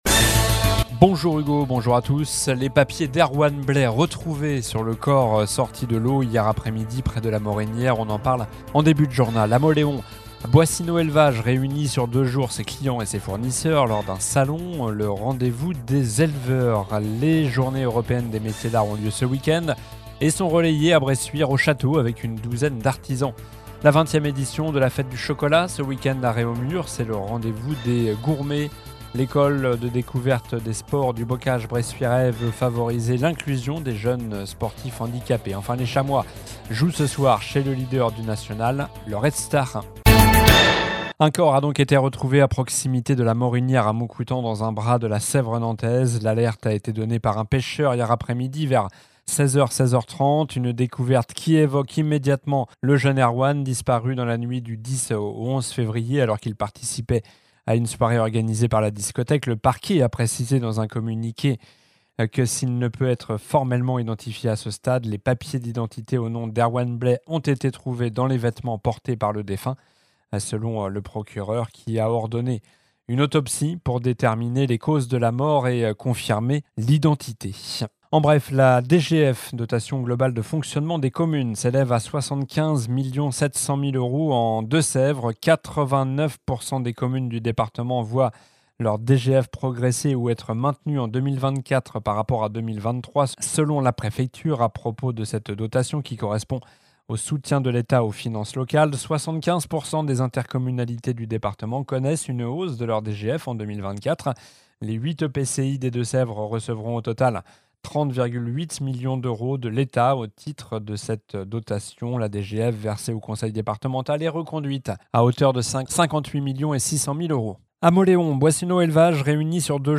Journal du vendredi 5 avril (midi)